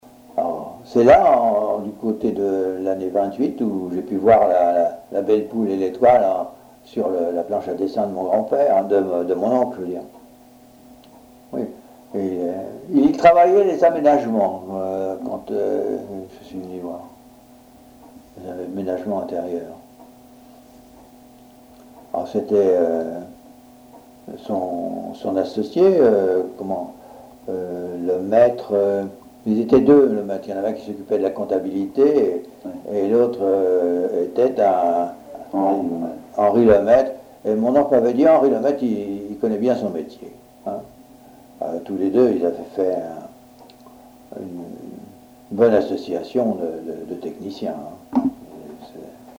Témoignages sur la construction navale à Fécamp
Catégorie Témoignage